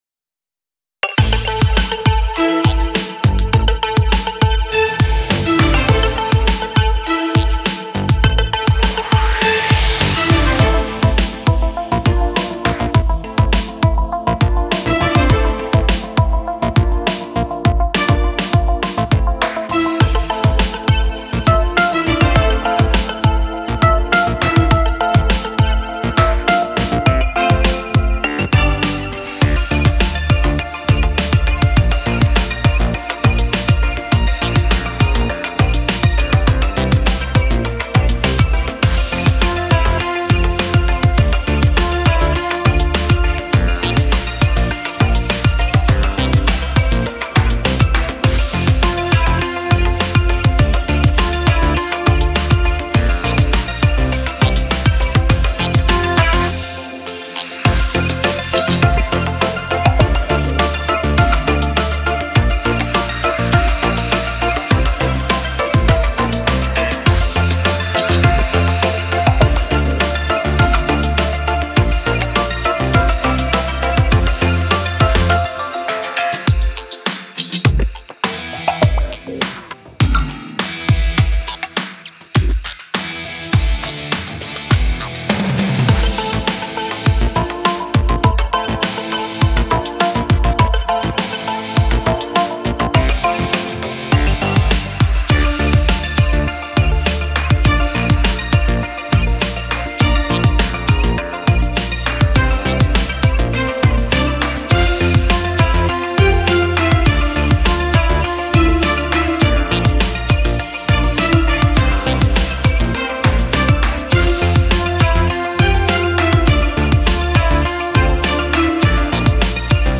Дитячі